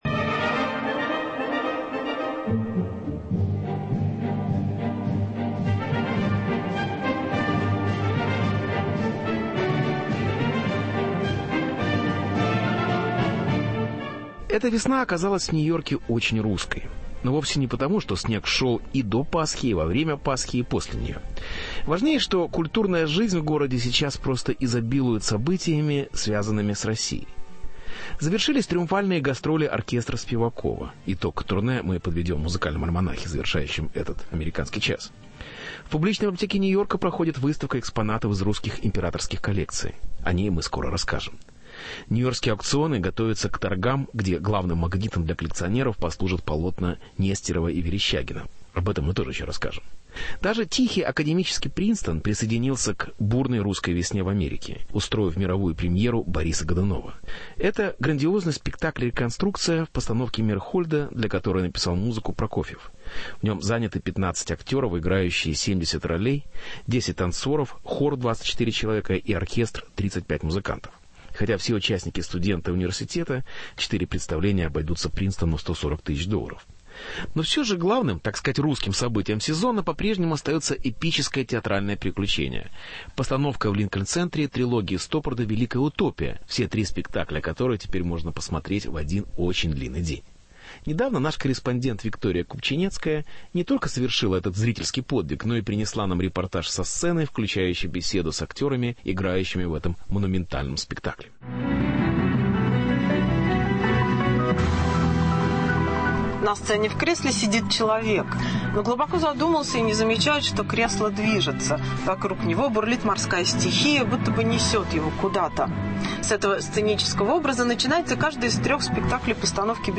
Репортаж со сцены. «Великая утопия» Стоппарда – глазами разыгрывающих ее актеров.